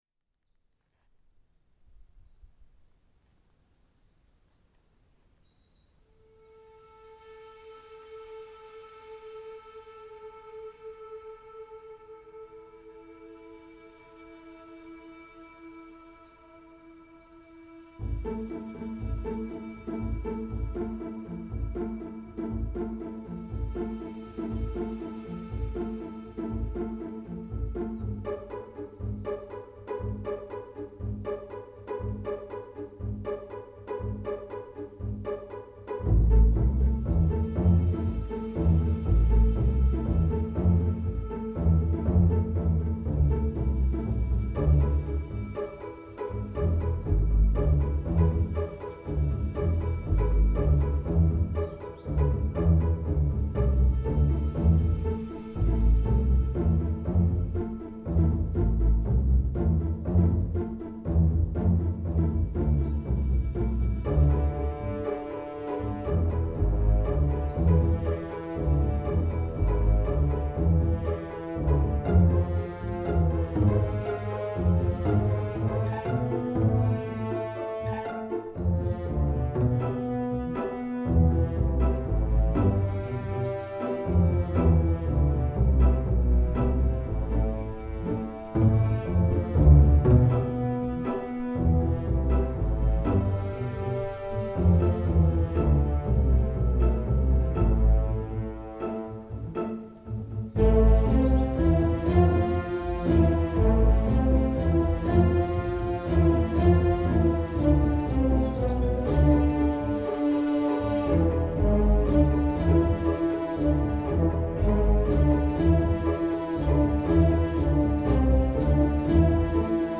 Kintbury birdsong reimagined